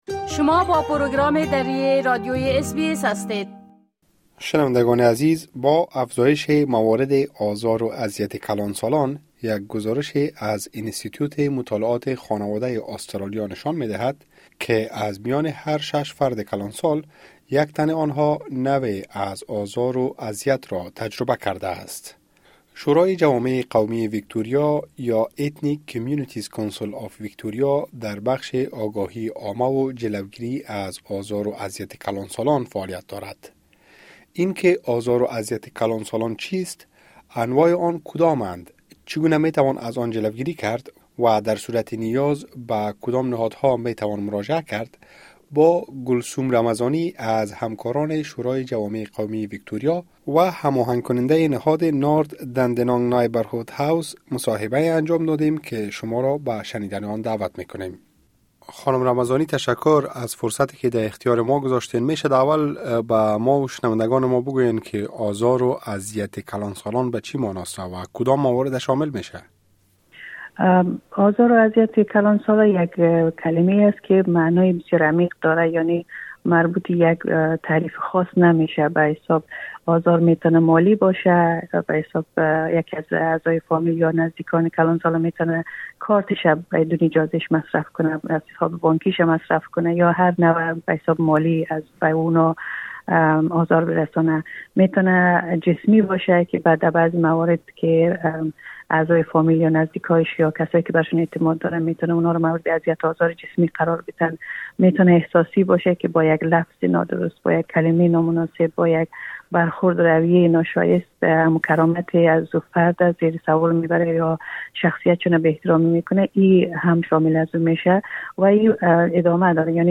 مصاحبه انجام دادیم.